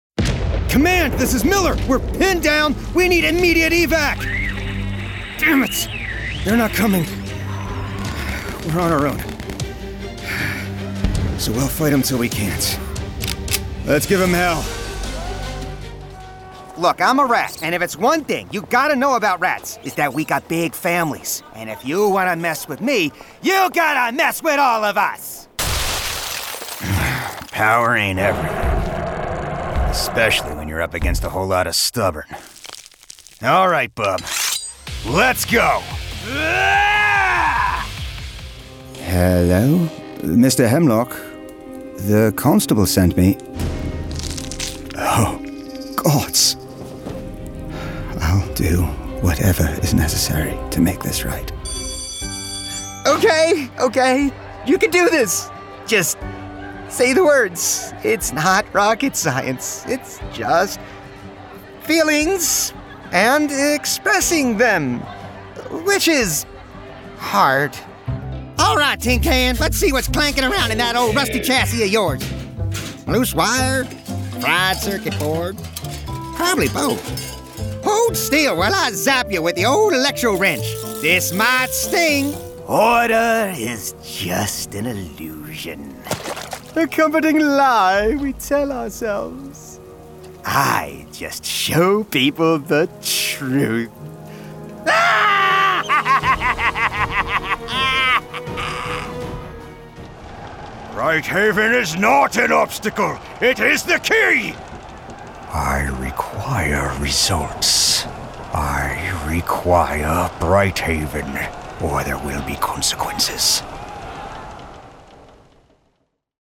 Browse our catalog of professional voice actor demos recorded, mixed, and produced in-house at Edge Studio NYC.
Genre: Animation